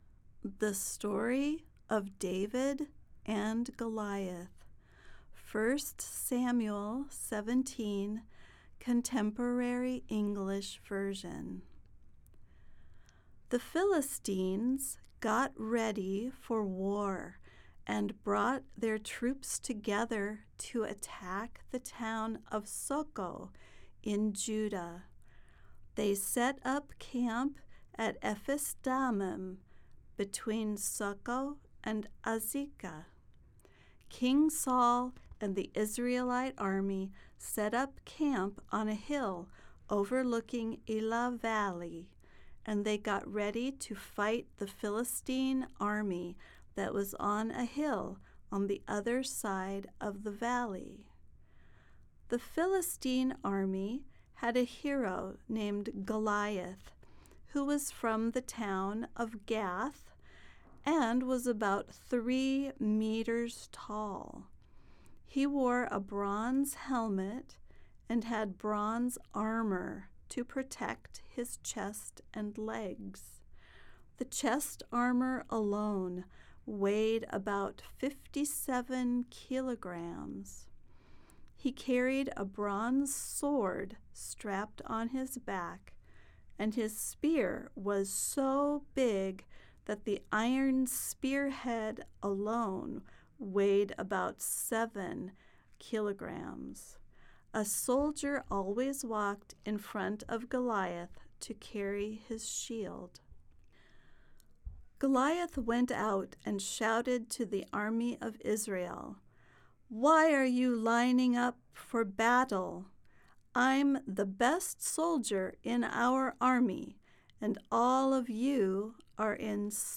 They are recorded in slow, easy English to make it easier for you to listen to spoken English.